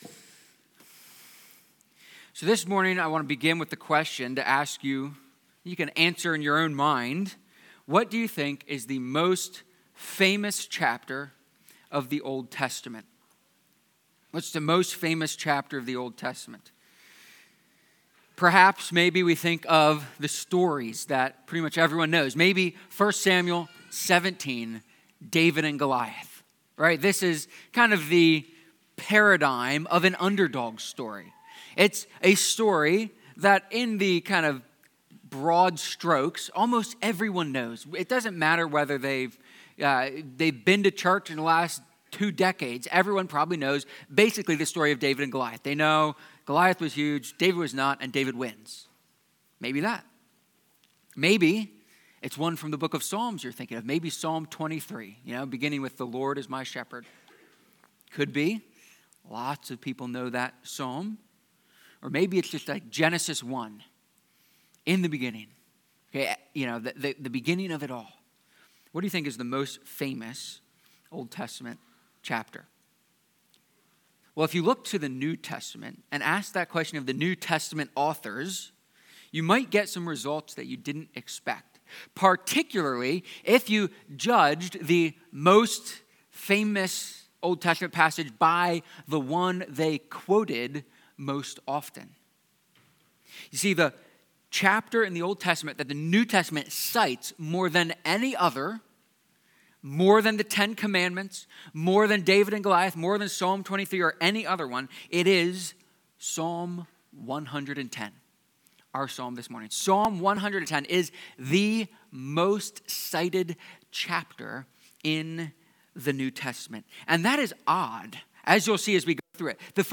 psalm-110-sermon.mp3